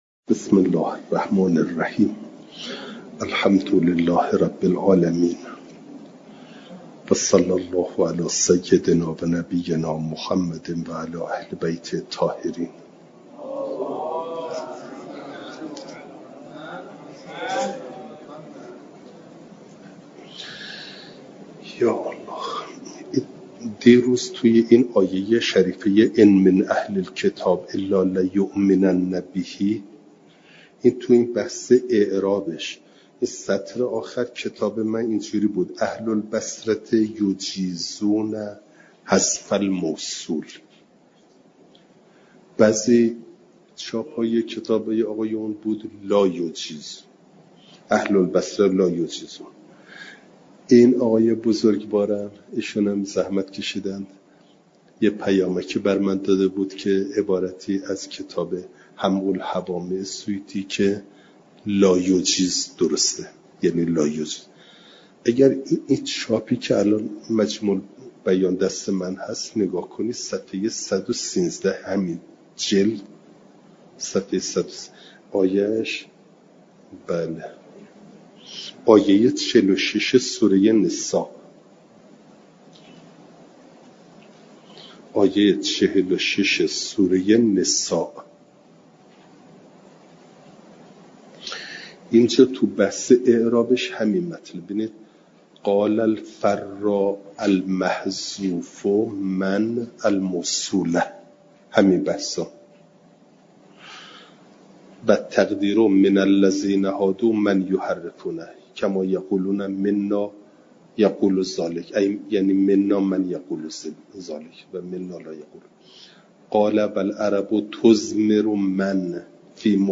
جلسه چهارصد و ششم درس تفسیر مجمع البیان